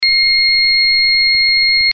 ZUMBADOR - SONIDO CONTINUO
Zumbador Electrónico Empotrable para cuadro ø 22,5 mm
Sonido contínuo.
dB 80
Continuo